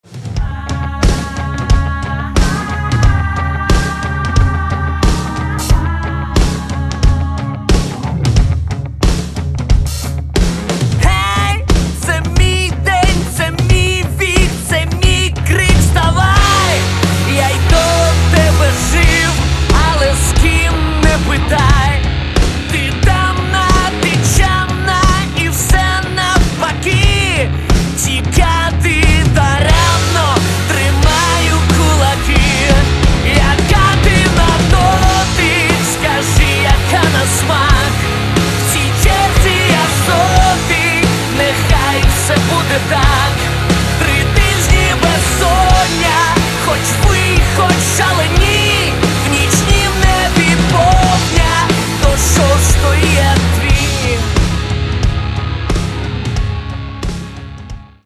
Rock & Alternative
A powerful album – at least, in terms of sounding.